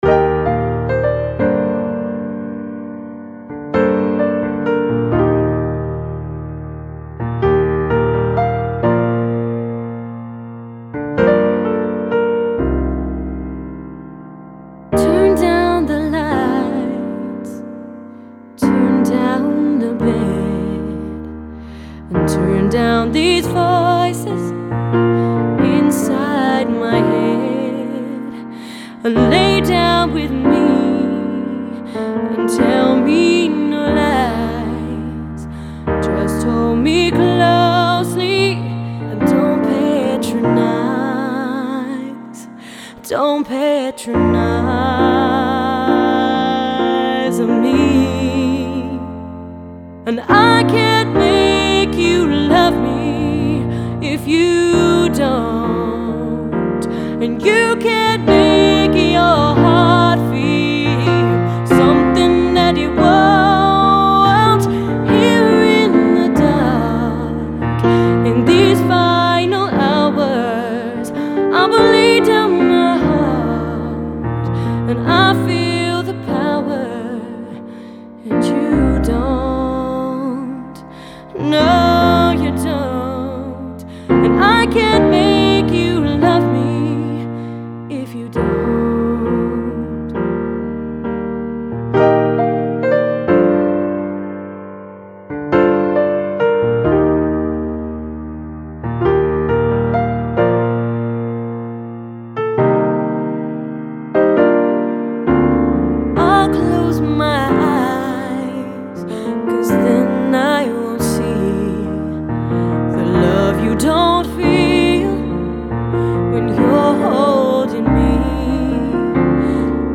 Fantastic Female Vocalist